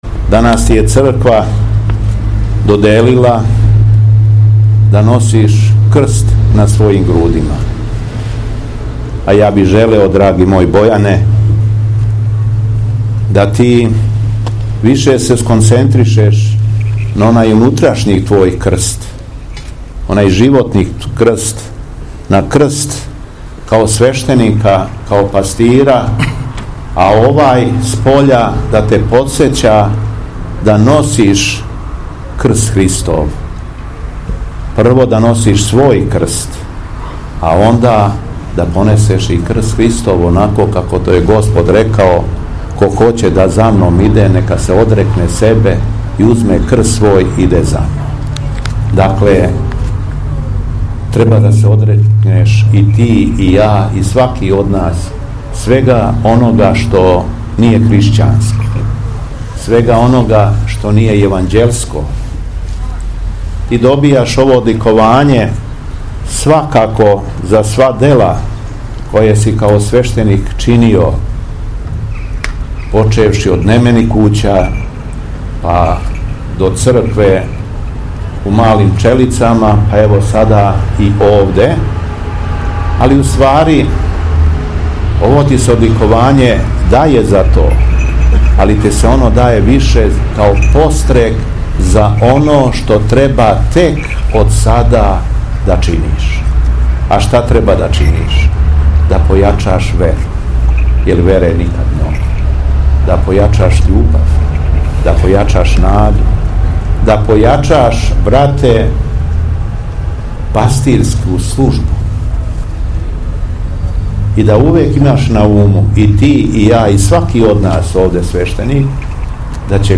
ОСВЕЋЕЊЕ ХРАМА СВЕТОГ НЕКТАРИЈА ЕГИНСКОГ ПРИ УНИВЕРЗИТЕТСКОМ КЛИНИЧКОМ ЦЕНТРУ КРАГУЈЕВАЦ
Духовна поука Његовог Високопреосвештенства Митрополита шумадијског г. Јована